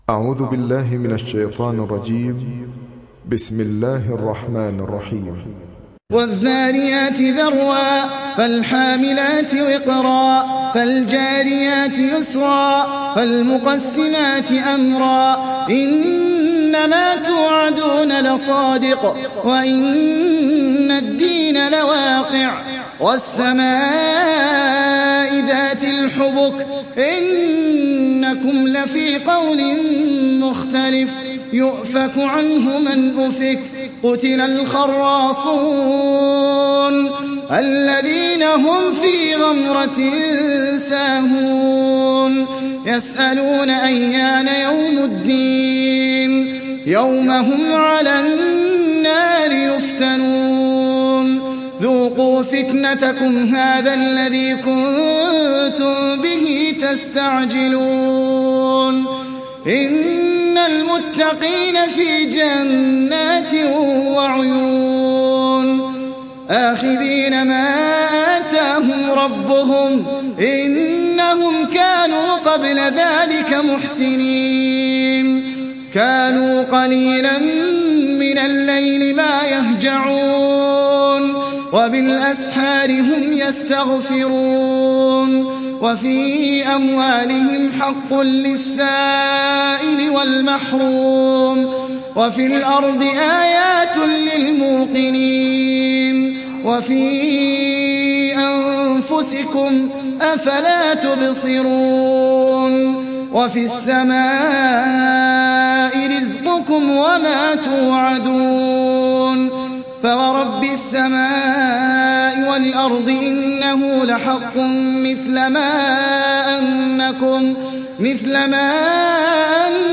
تحميل سورة الذاريات mp3 بصوت أحمد العجمي برواية حفص عن عاصم, تحميل استماع القرآن الكريم على الجوال mp3 كاملا بروابط مباشرة وسريعة